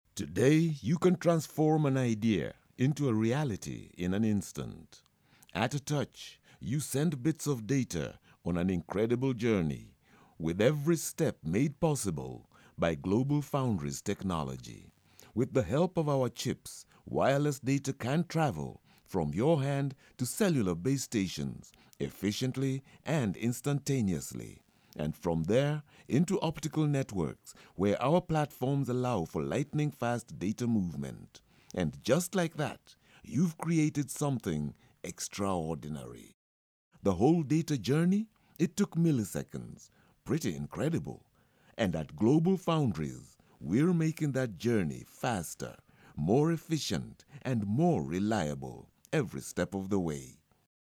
Voice demo 3
I do voice-overs